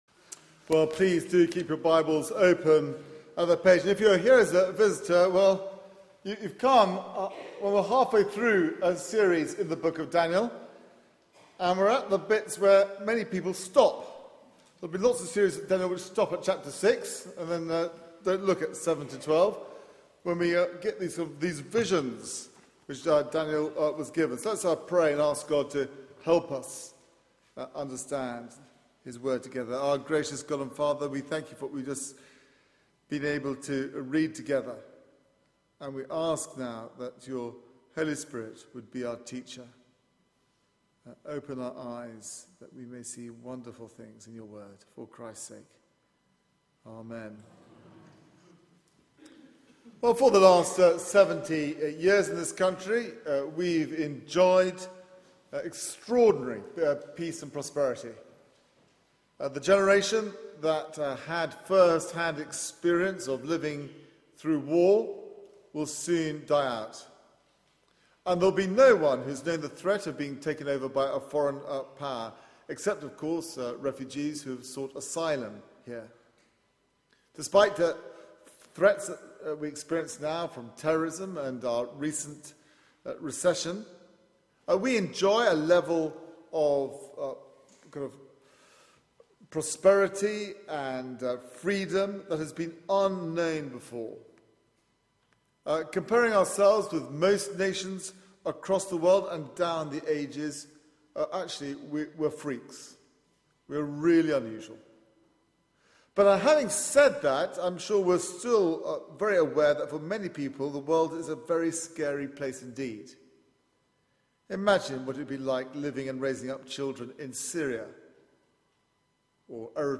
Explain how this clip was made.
Media for 9:15am Service on Sun 27th Oct 2013 09:15 Speaker